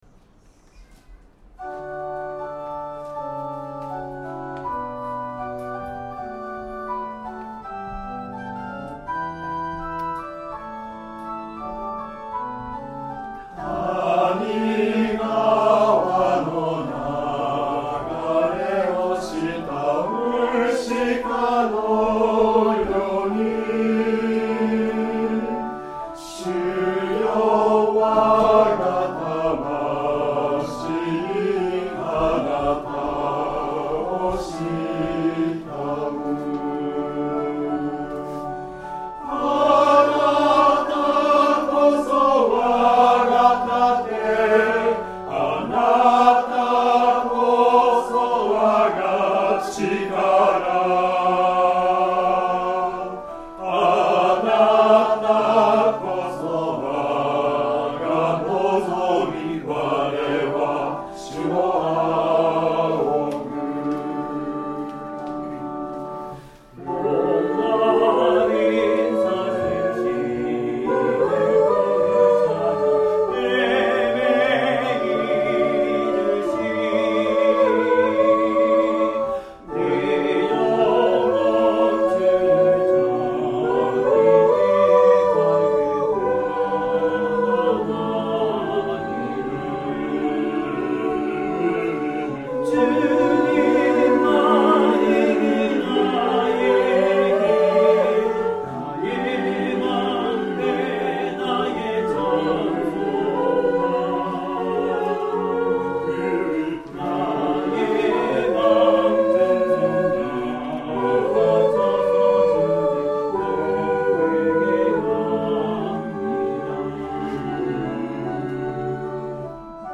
Tonality = D
練習: 2024/9/1 聖歌隊練習 Take-2 全体